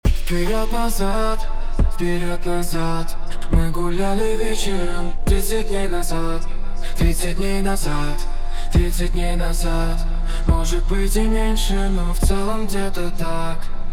Как сделать такую обработку вокала как в примере
Подскажите цепочку обработки, и может манеру пения, чтобы получить похожий эффект?